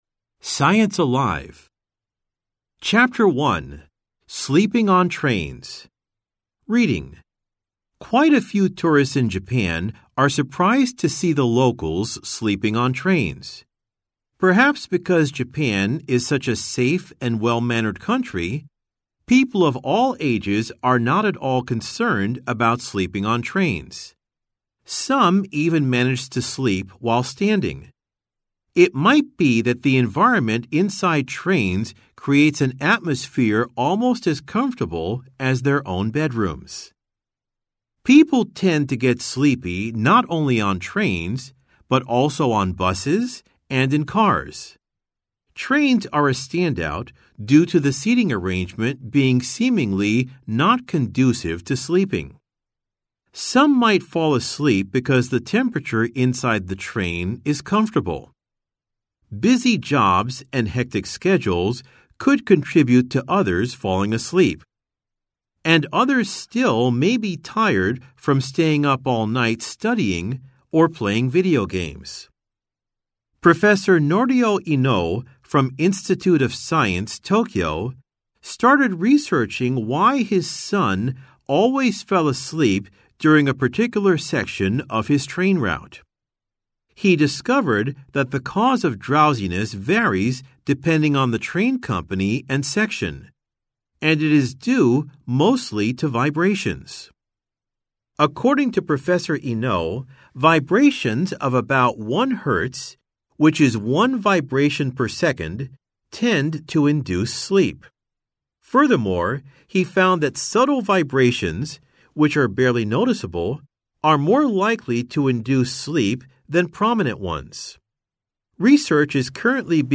Audio Language Amer E